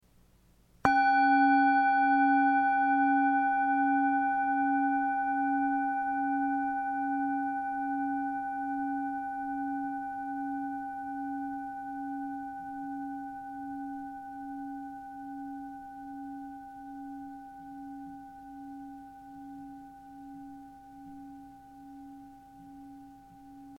KLANGPROBEN
4 Klangschalen für die Klangmassage
Sehr gute Klangqualität - sehr lange anhaltender Klang
Grundton 279,97 Hz
1. Oberton 791,32 Hz